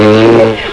snd_9010_Fart.wav